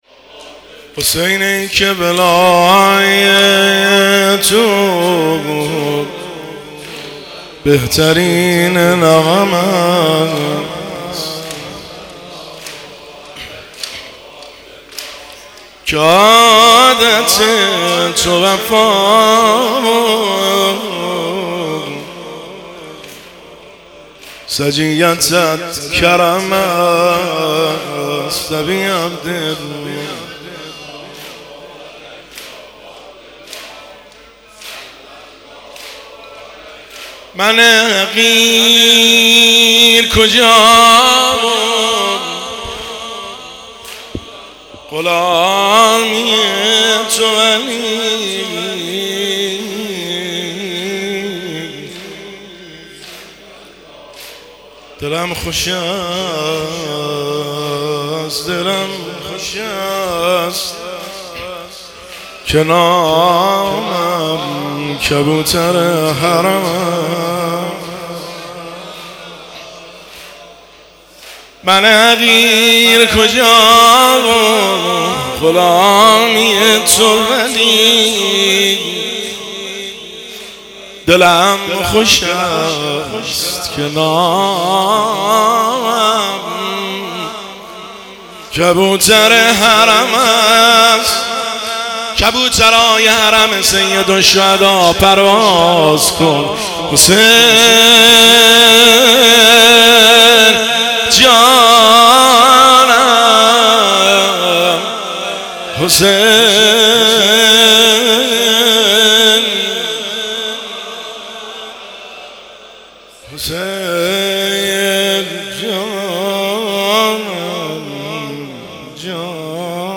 جلسه هیئت هفتگی
هیئت هفتگی 24 اردیبهشت 1404
شعرخوانی